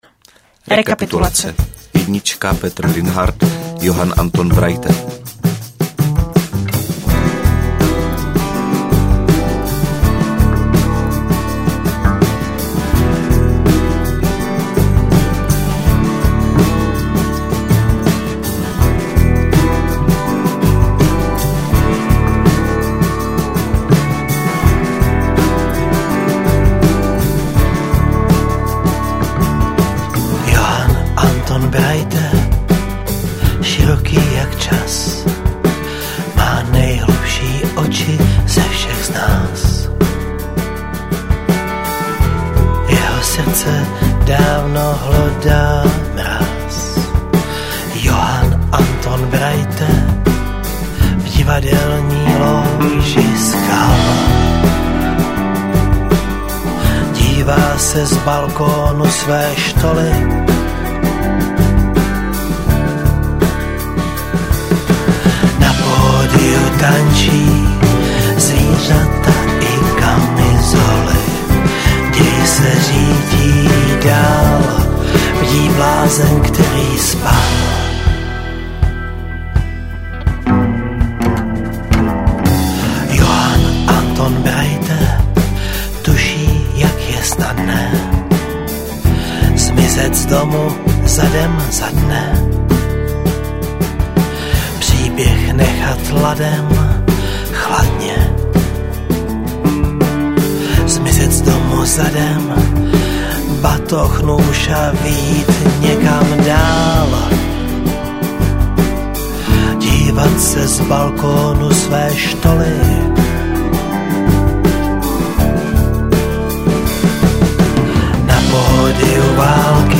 Český tým obsadil 13. místo a my jsme hovořili s třemi hráči z Čech krátce po jejich návratu ze šampionátu.